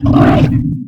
ogg / general / combat / creatures / alien / it / attack2.ogg